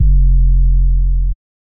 808 (Intro).wav